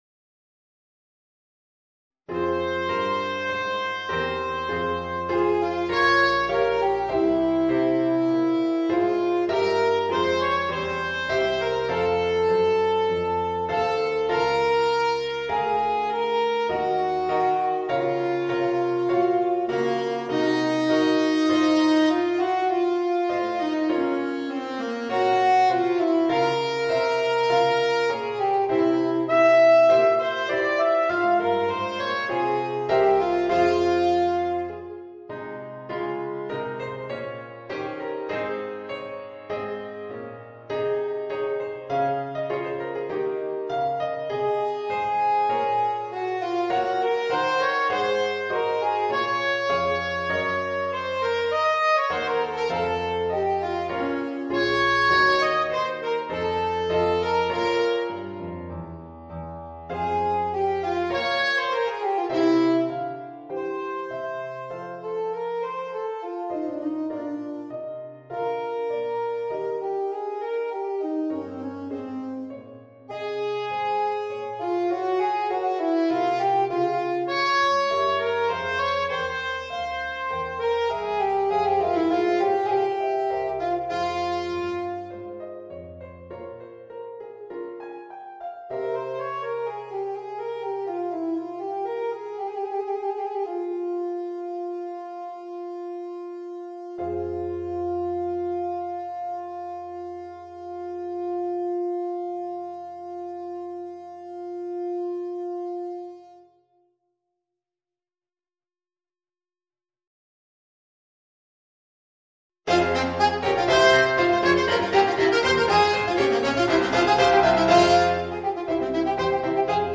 Voicing: Alto Saxophone and Organ